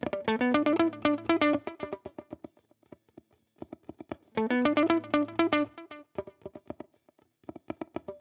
11 Pickin Guitar PT1.wav